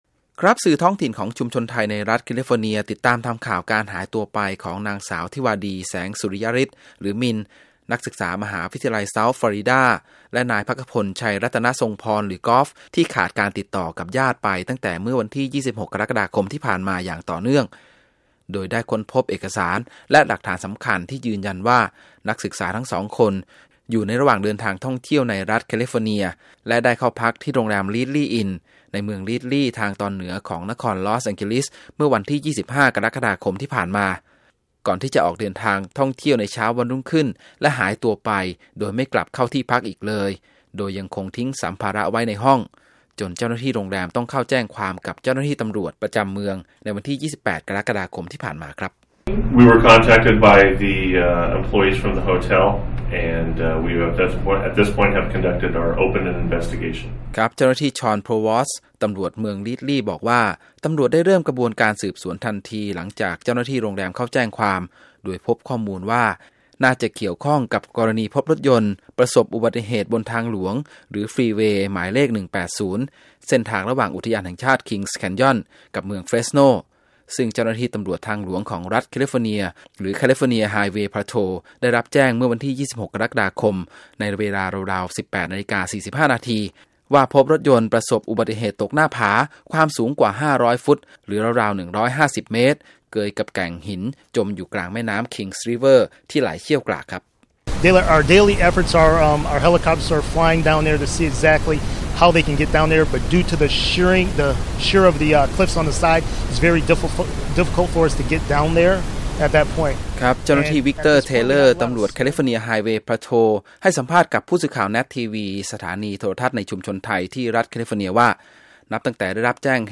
นายธานี แสงรัตน์ กงสุลใหญ่ ณ นคร ลอส แองเจลิส ให้สัมภาษณ์กับ วีโอเอ ภาคภาษาไทย